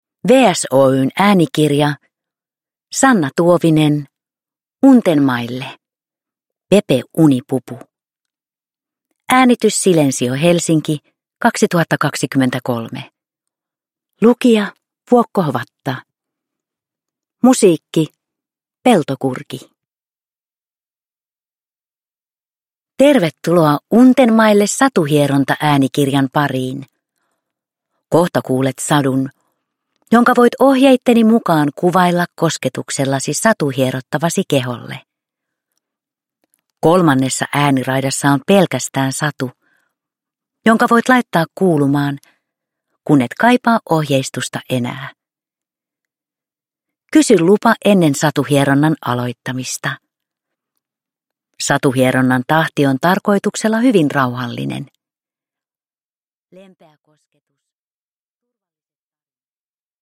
Produkttyp: Digitala böcker
Untenmaille äänikirjat sisältävät teoksia varten sävelletyn rauhoittavan ja elämyksellisen musiikillisen äänimaiseman.